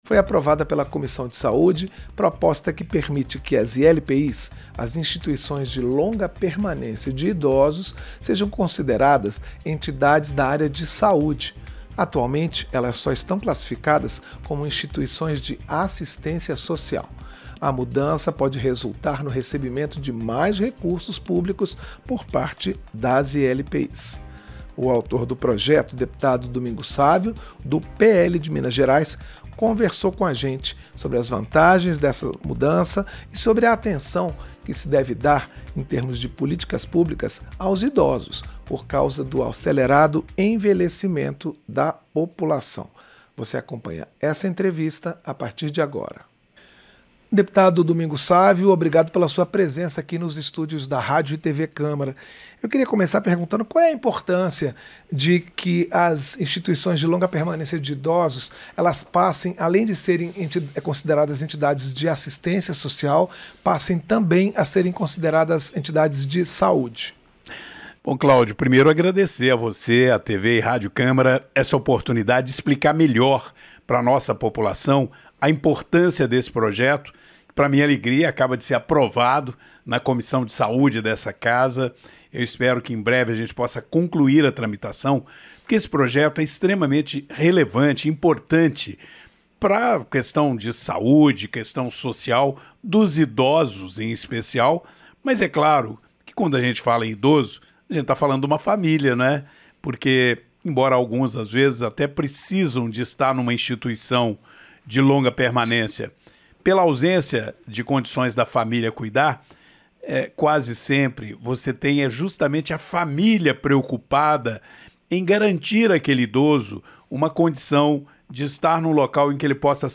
Entrevista - Dep. Domingos Sávio (PL-MG)